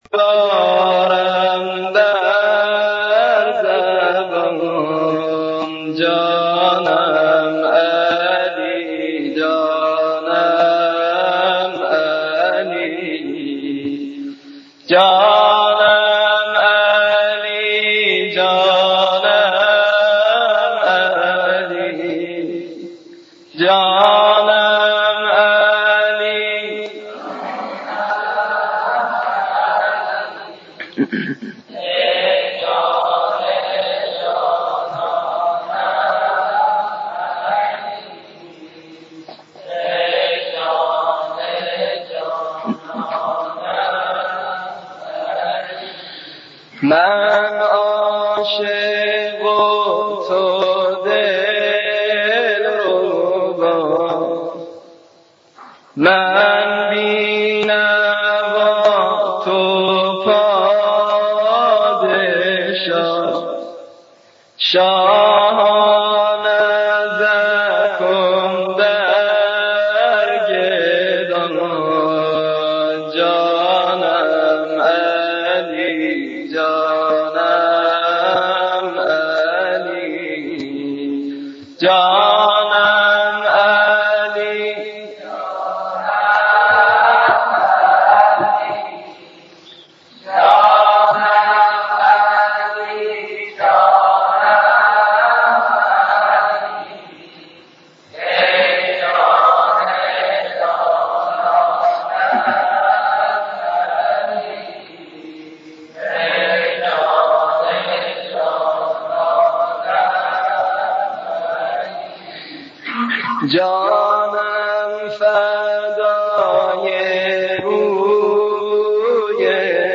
مدیحه خوانی ولادت حضرت امیرالمؤمنین 13 رجب سال 1422هـ.ق - مدائح و مراثی - مداح شماره 1 | مکتب وحی
مدیحه خوانی ولادت حضرت امیرالمؤمنین 13 رجب سال 1422هـ.ق